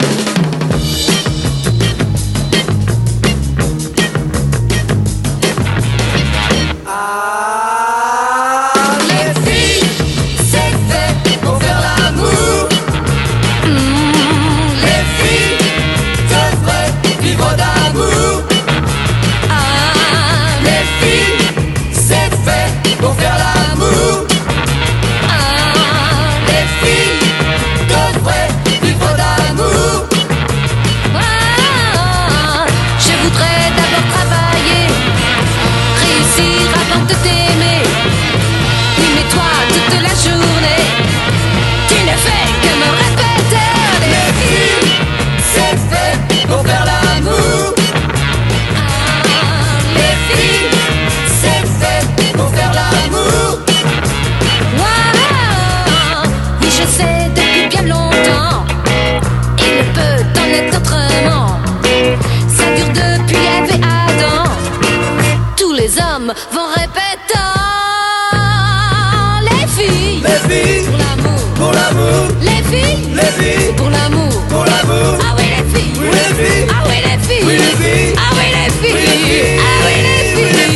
WORLD / OTHER / FRENCH / 60'S BEAT / GIRL POP / FUNK
選りすぐりのフレンチ・グルーヴをコンパイル！ フランス産グルーヴを60年代の音源を中心にセレクト！